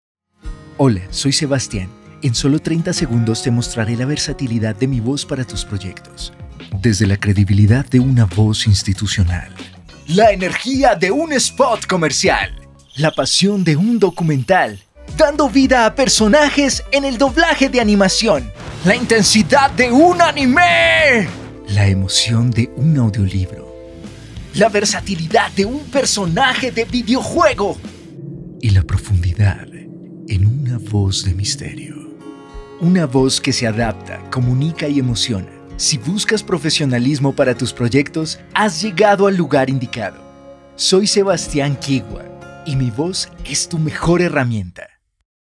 Español (Latinoamericano)
Español (Colombia)
Articulado
Seguro
Creíble